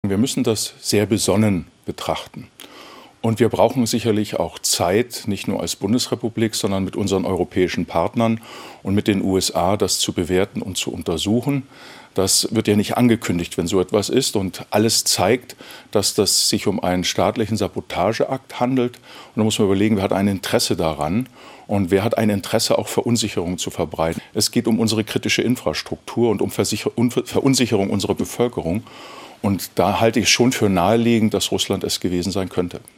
Der CDU-Außenpolitiker Roderich Kiesewetter im ARD-Morgenmagazin.